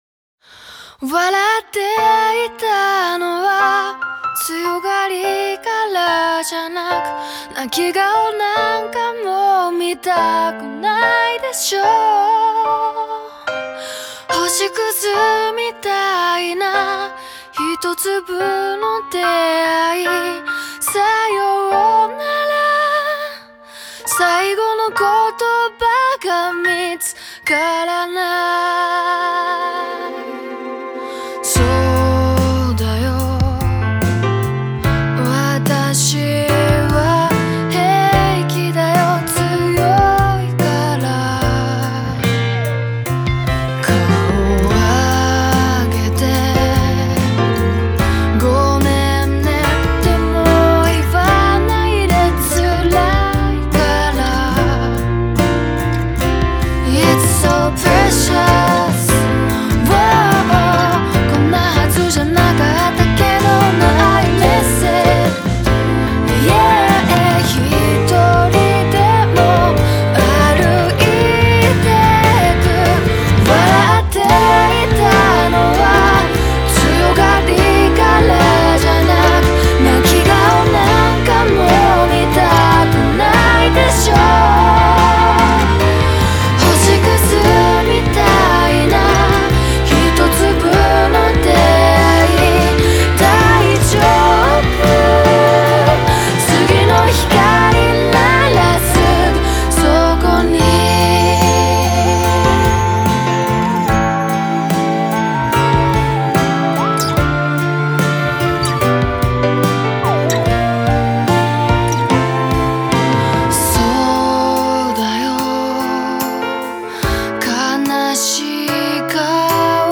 음악 공간/J-POP